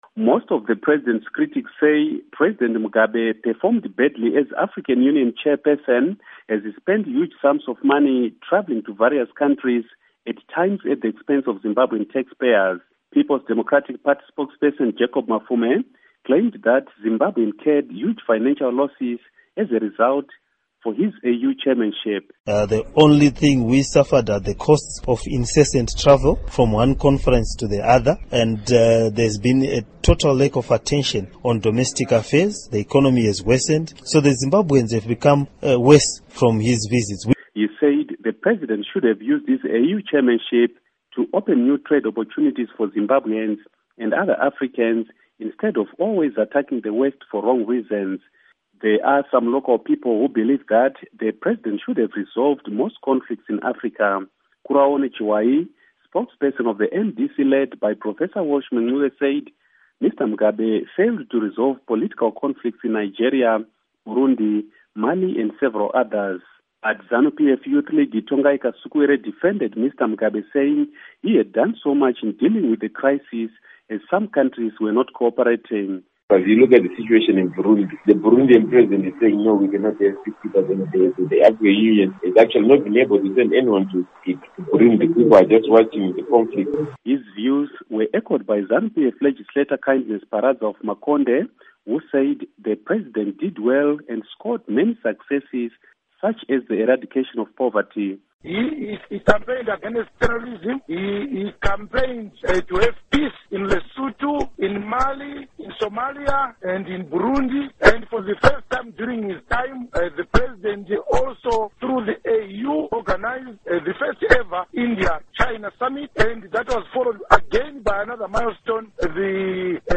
HARARE —